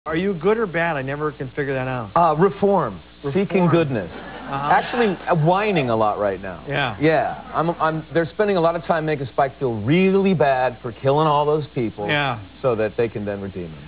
James Marsters was a guest on "The Late Late Show with Craig Kilborn" 21 January. During the interview, there was this exchange:
The  129K WAV of this Q&A